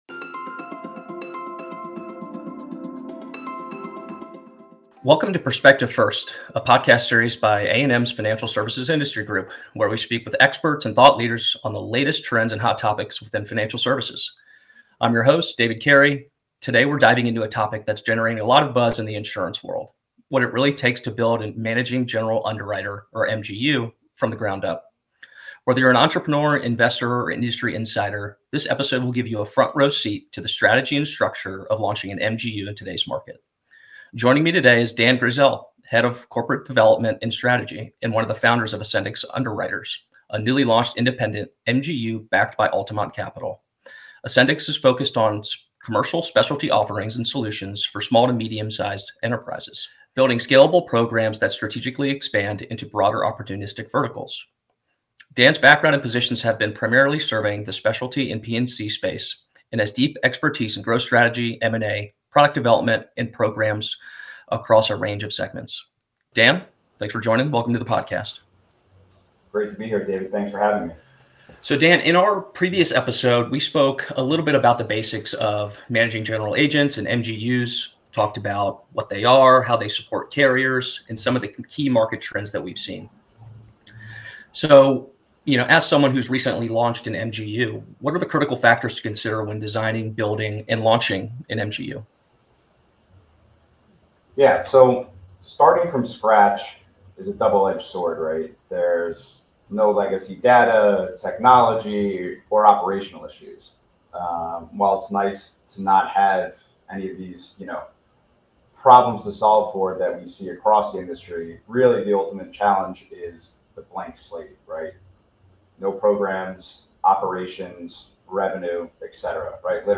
Tune in for an insightful conversation you won't want to miss.